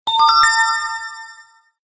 File Type : Sms ringtones